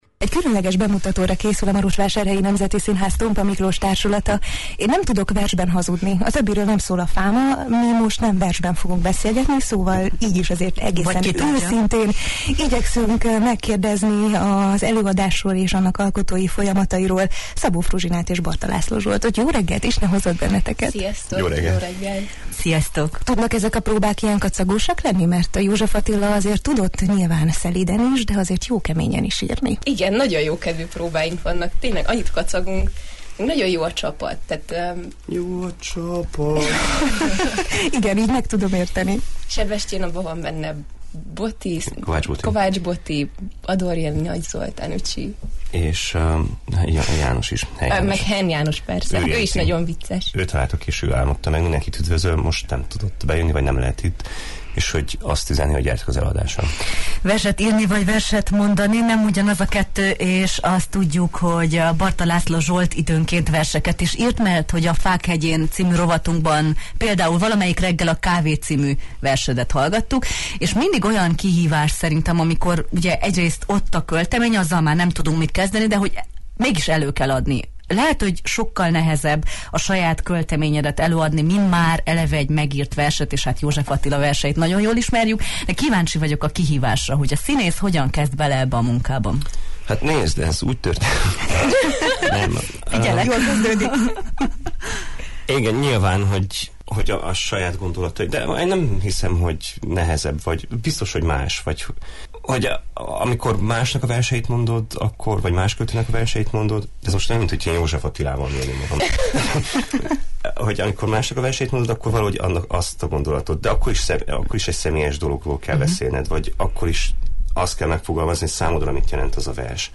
színművészekkel beszélgettünk az előadás kapcsán a Jó reggelt, Erdély!-ben: